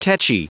Prononciation du mot tetchy en anglais (fichier audio)
Prononciation du mot : tetchy